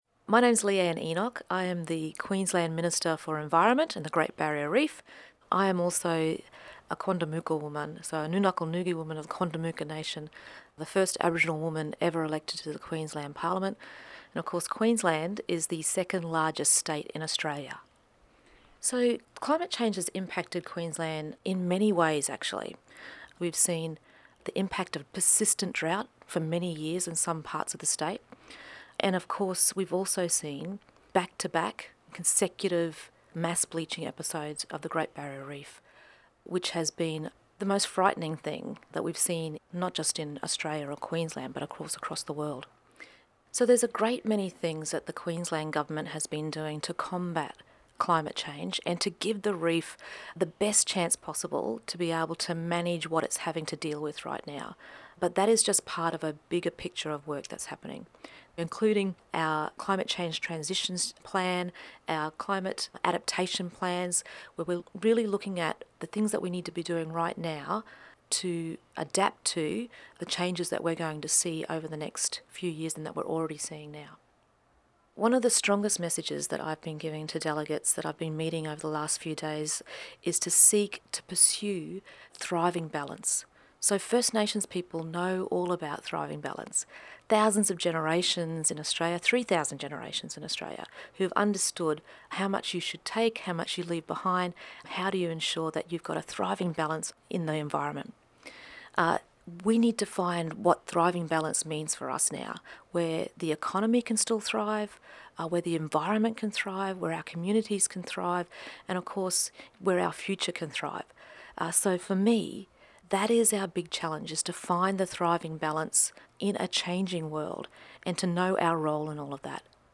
Here are some stories from the front lines of climate change that we gathered at the Global Climate Action Summit in San Francisco in mid-September (listen to each person talk by clicking the audio players below the images).
6. Leeanne Enoch, Queensland minister for environment and the Great Barrier Reef, Quandamooka member